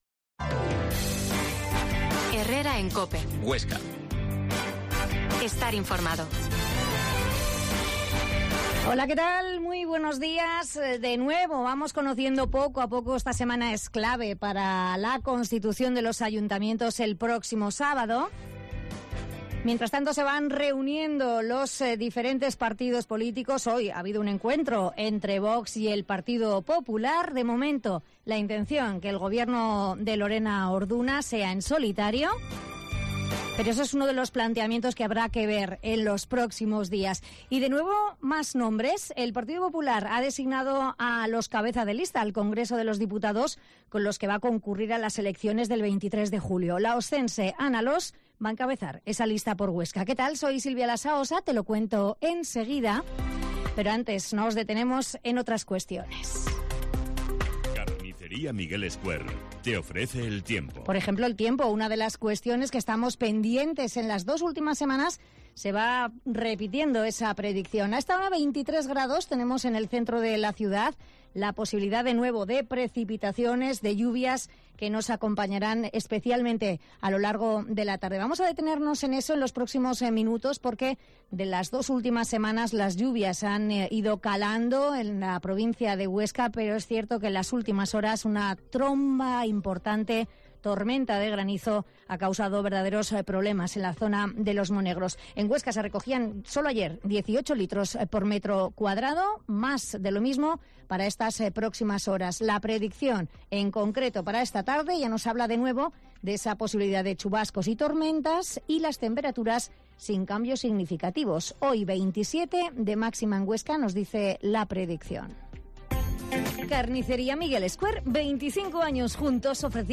Herrera en COPE Huesca 12.50h Entrevista al alcalde de Frula-Almuniente, Joaquin Monesma